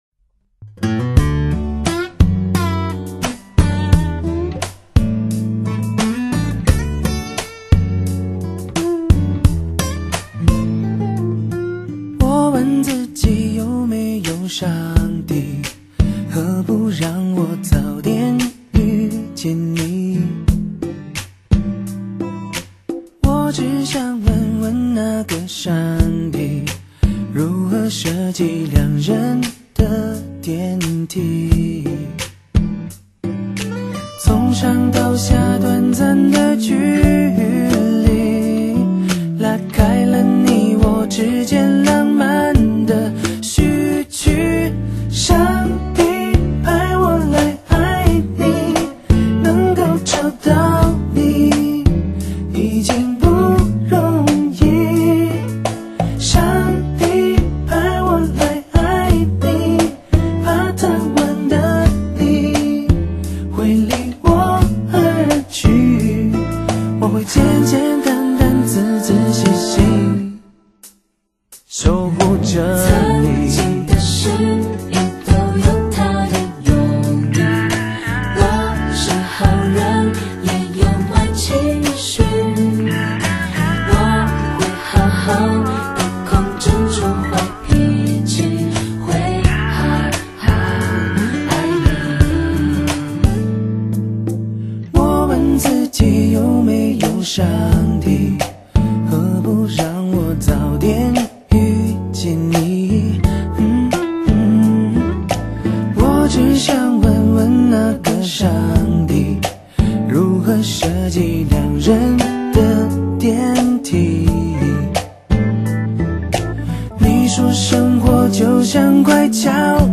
无敌的HIFI录音